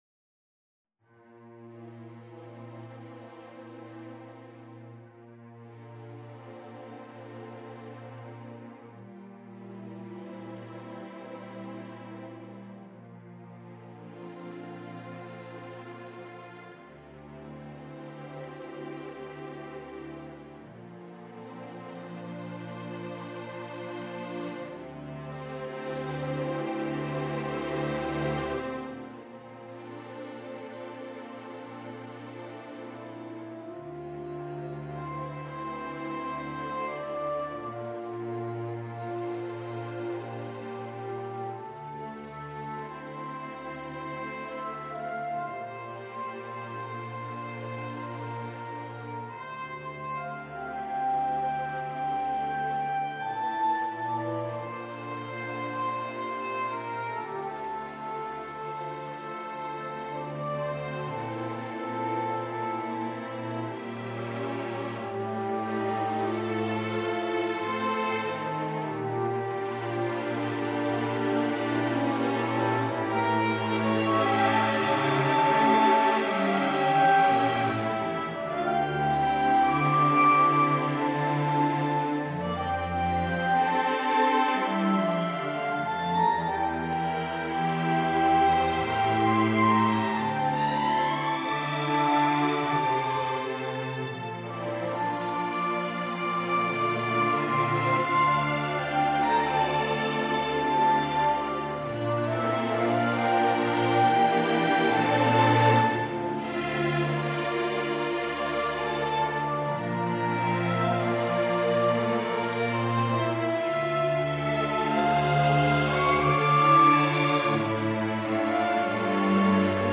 Elegy for soprano saxophone and string quartet.
is a slow and poignant expression of sadness and loss.